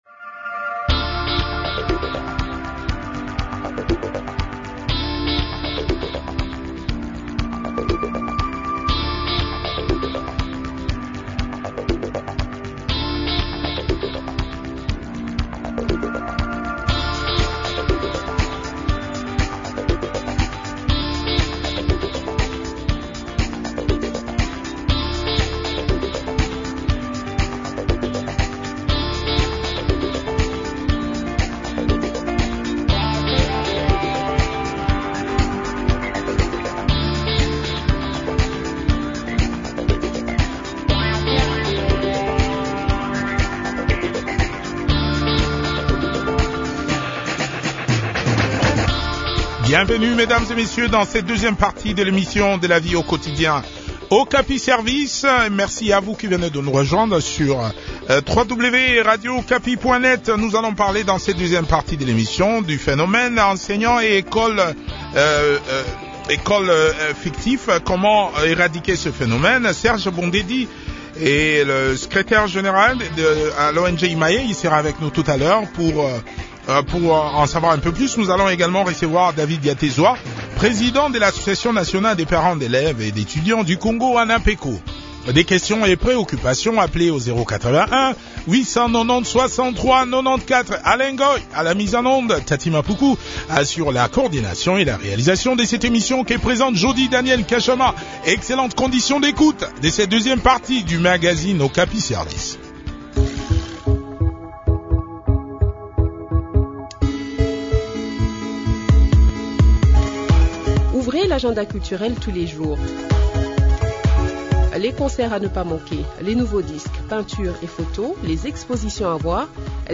a aussi participé à cet entretien.